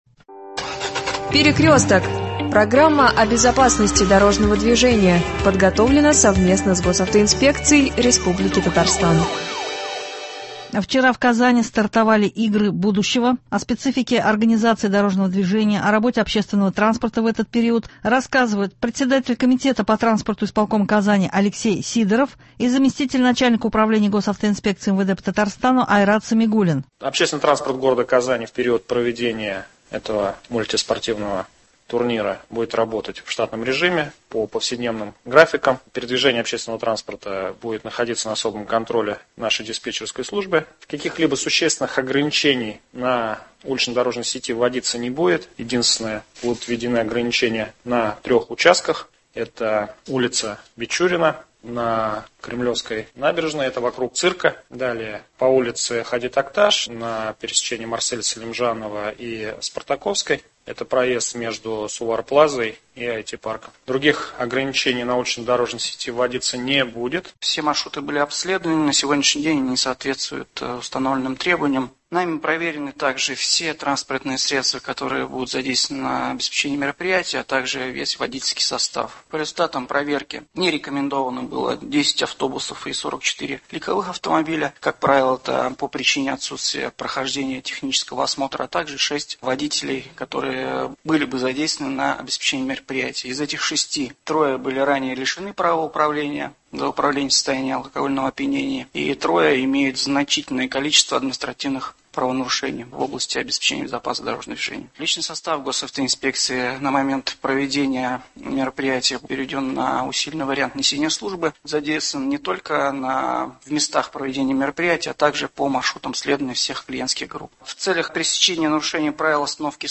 Вчера в Казани стартовали Игры будущего, о специфики организации ДД и работе ОТ в этот период рассказывают Председатель комитета по транспорту Исполкома Казани Алексей Сидоров и заместитель начальника управления госавтоинспекции МВД по РТ Айрат Самигуллин.
По прежнему – особое внимание безопасности детей, у микрофона зам нач УГАИ МВД по Татарстану Дамир Бикмухаметов